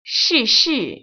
[shìshì] 스스  ▶